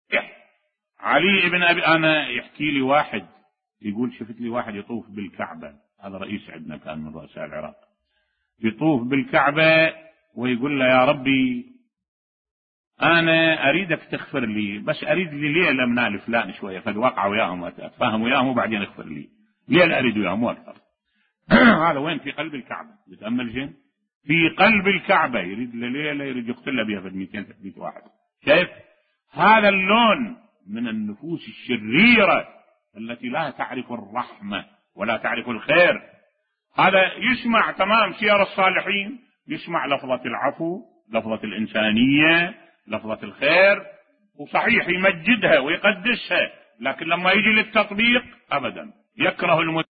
ملف صوتی قصة احد رؤوساء العراق في بيت الله الحرام بصوت الشيخ الدكتور أحمد الوائلي